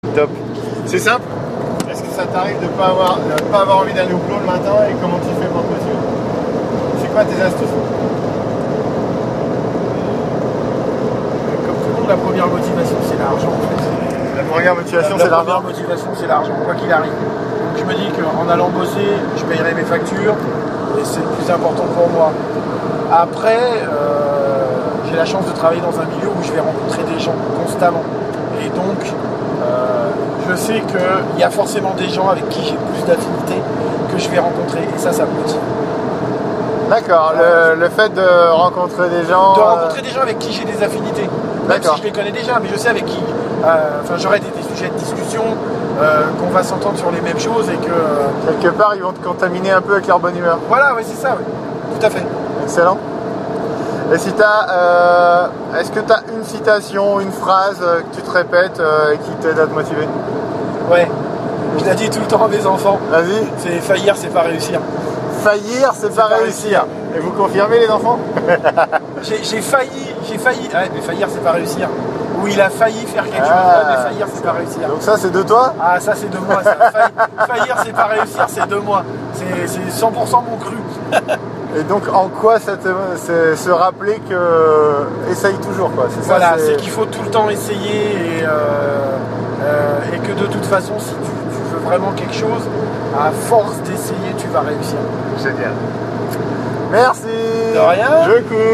L’interview :
Il y a du bruit derrière (nous sommes en voiture), c’est normal, c’est du micro-trottoir, c’est du spontané !